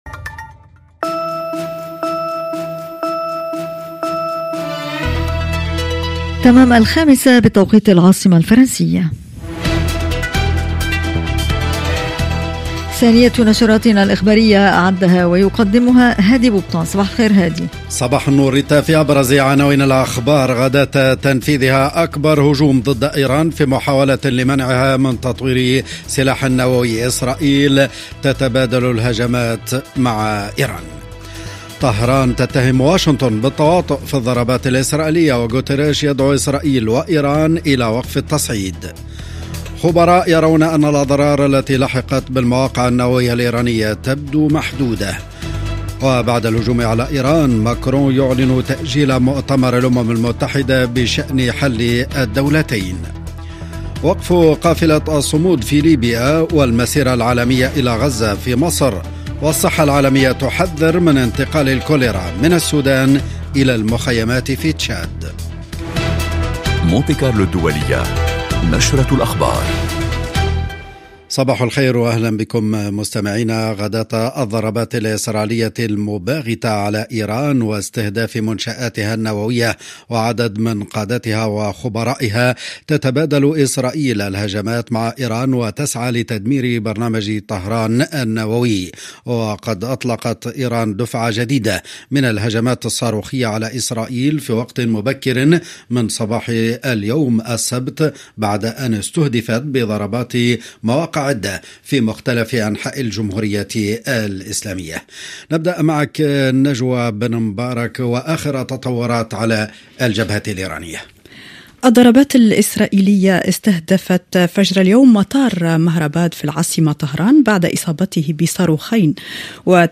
نشرة أخبار الساعة 3:00 بتوقيت غرينتش